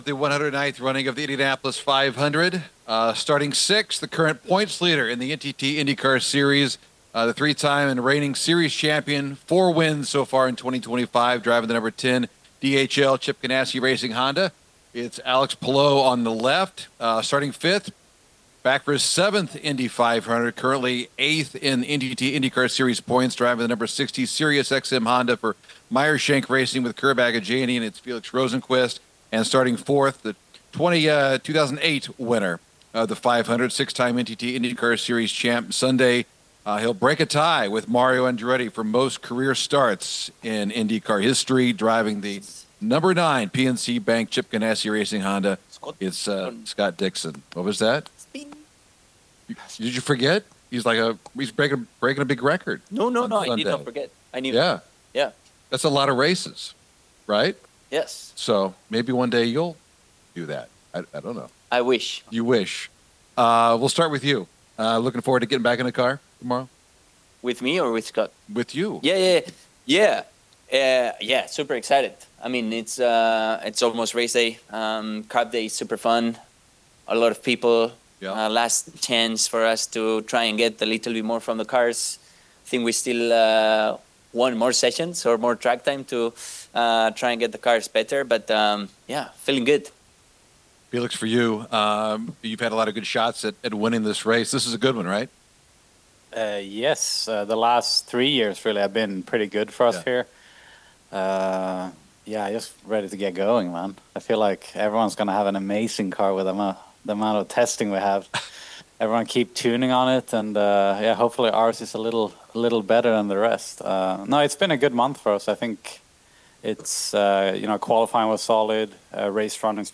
2025 Indianapolis 500 Drivers Media Day Press Conference: Row 2 with Alex Palou, Felix Rosenqvist and Scott Dixon